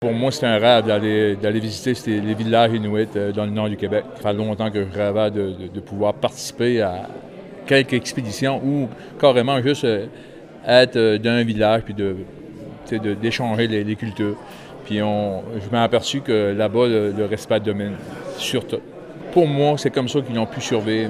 Les Rangers juniors des Îles tenaient une cérémonie, hier soir à la mairie de Cap-aux-Meules, afin d’accueillir une escouade de Rangers de passage dans l’archipel, dans le cadre de l’exercice AQIKGIK.